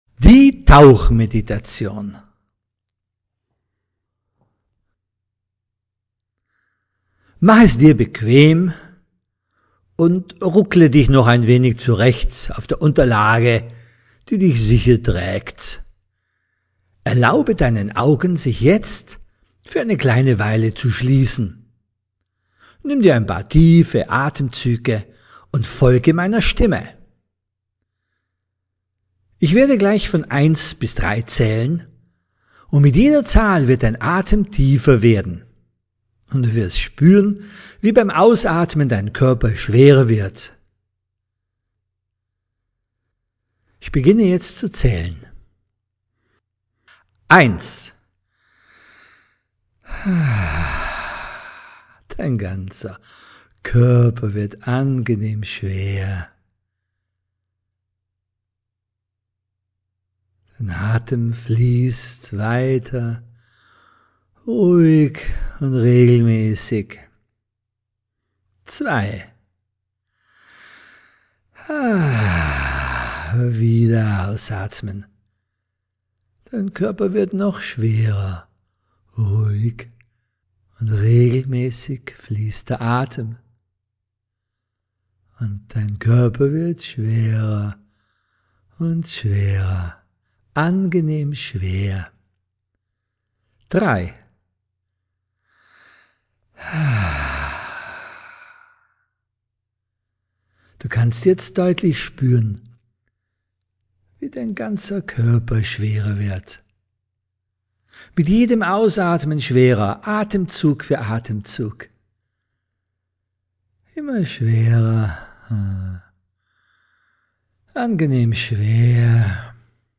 01-Relax10-Innere-Ruhe_-Tauchmeditation-WEB.mp3